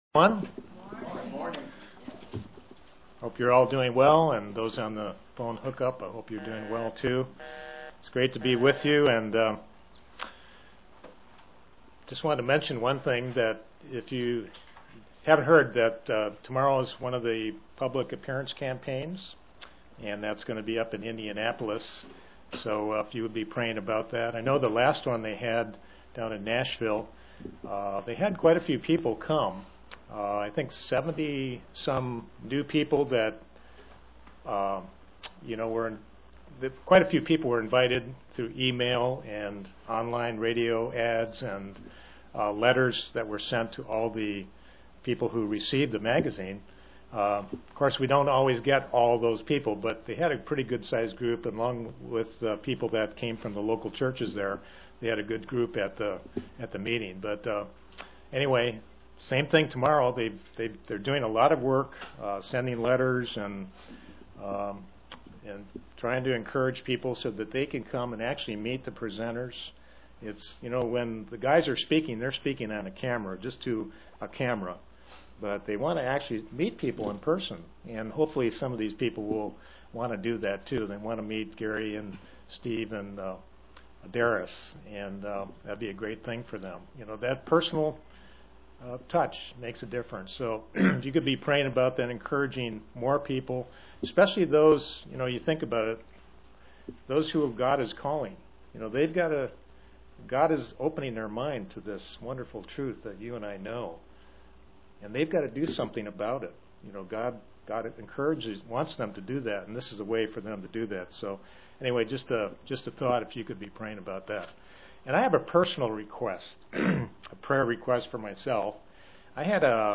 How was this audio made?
A comparison of the modern Kevlar bullet proof vest with the breastplate of righteousness in Ephesians 6:11-17 (Presented to the London KY, Church)